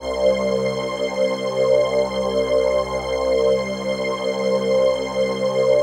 PHASEPAD03-LR.wav